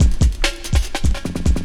16 LOOP07 -L.wav